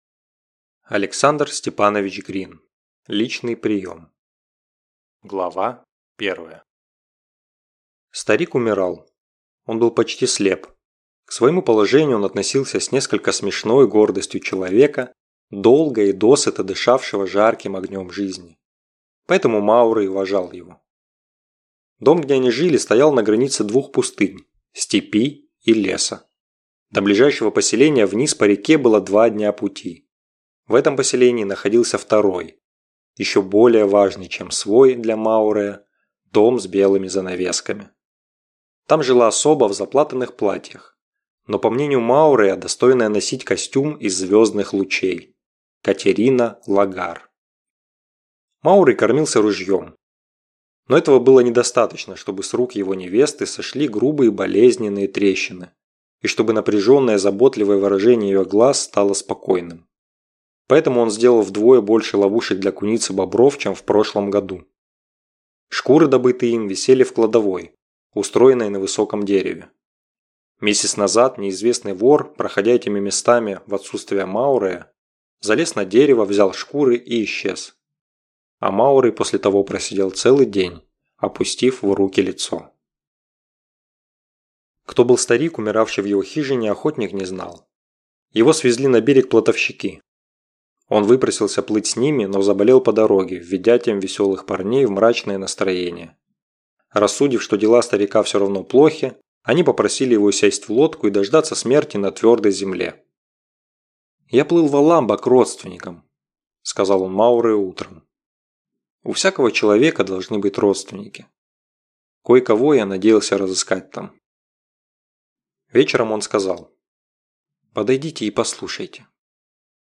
Aудиокнига Личный прием Автор Александр Грин.